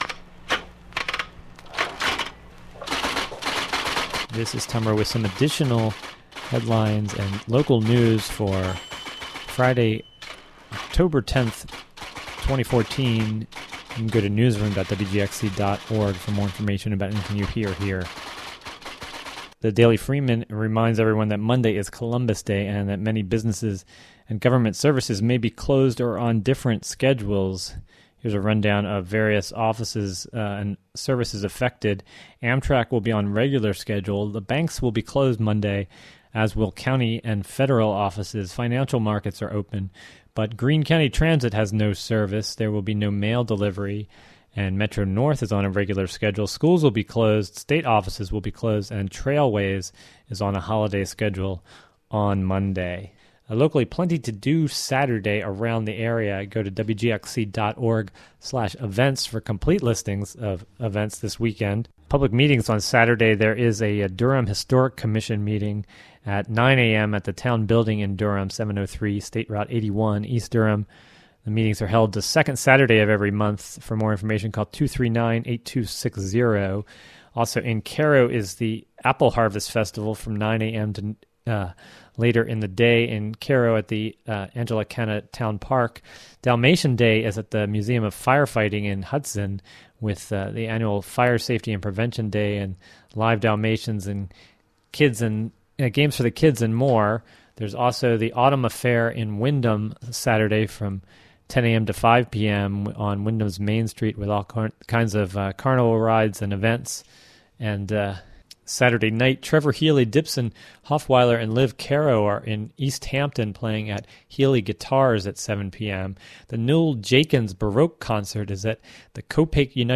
State Sen. Cecilia Tkackyk spoke with WGXC after t...
Also, Ellen Thurston, a Hudson Supervisor, addresses the CEDC issue during the "WGXC Afternoon Show."